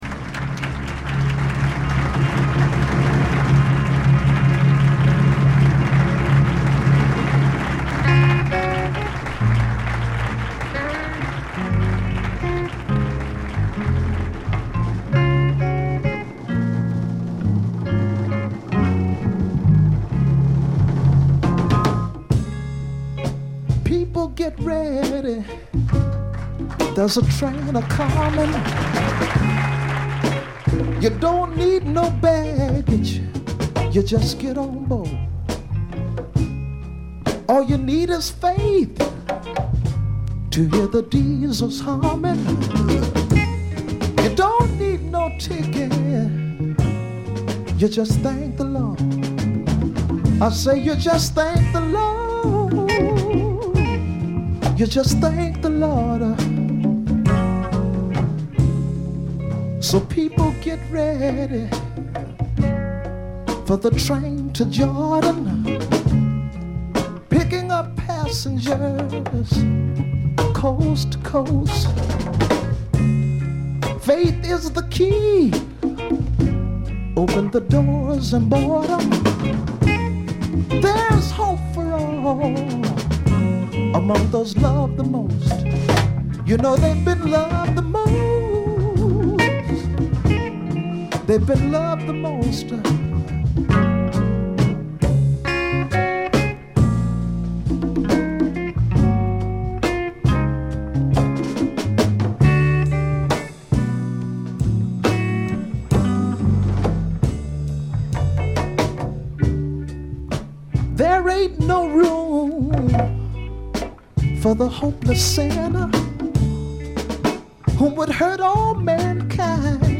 部分試聴ですがほとんどノイズ感無し。極めて良好に鑑賞できると思います。実際の音源を参考にしてください。
試聴曲は現品からの取り込み音源です。
Bongos, Congas, Percussion [Tumbas]